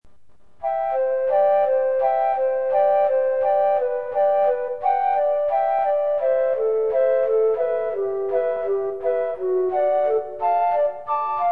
flut.mp3